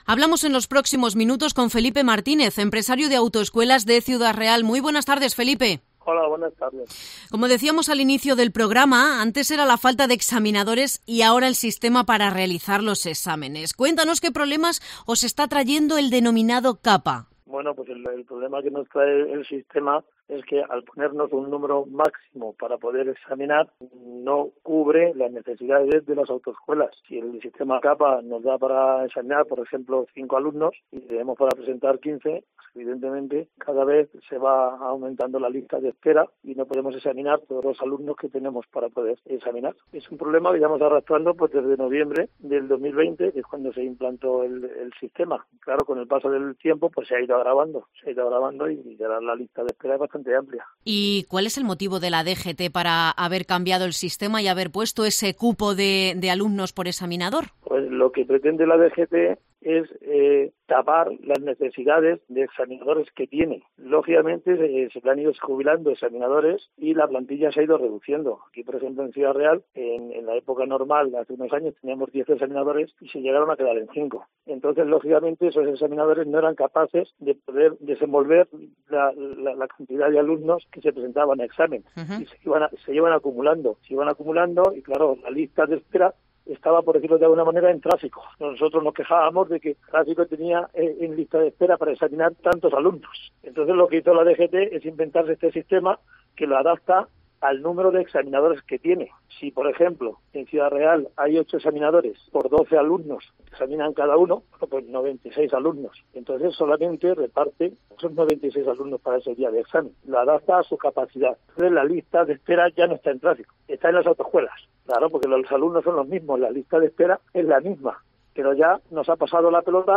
propietario de autoescuela